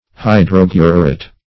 Hydroguret \Hy*drog"u*ret\, n.
hydroguret.mp3